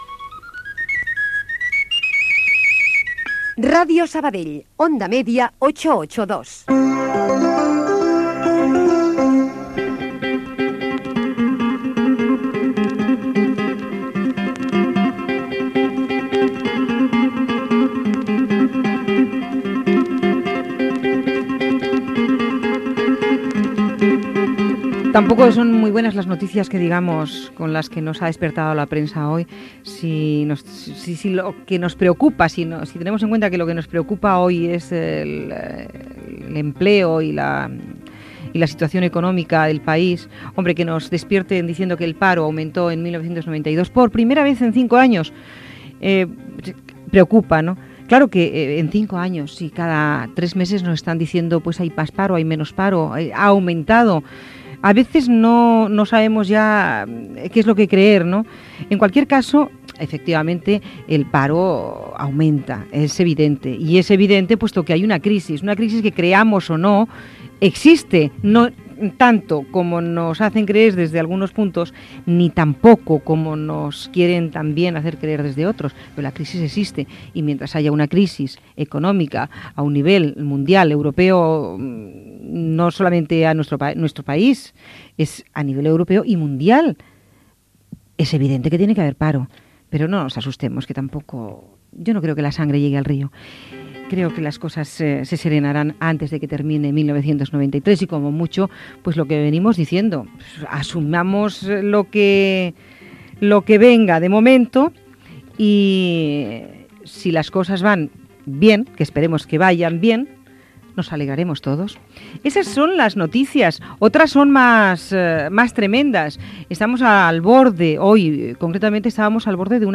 Indicatiu de l'emissora i comentari d'algunes notícies del dia: l'augment de les xifres de l'atur a Espanya, la possible Guerra d'Iraq, el possible divorci del Príncep de Gal·les de Diana Spencer.
Entreteniment